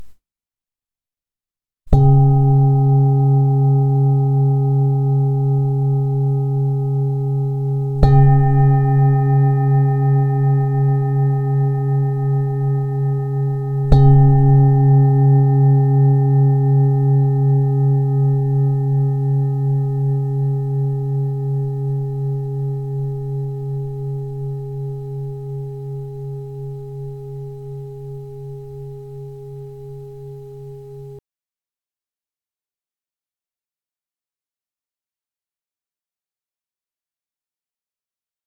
Zdobená tibetská mísa C#3 23,5cm
Nahrávka mísy úderovou paličkou:
Jde o ručně tepanou tibetskou zpívající mísu dovezenou z Nepálu.
Na dně mísy je vyobrazen květ života a její zvuk rezonuje s kořenovou čakrou.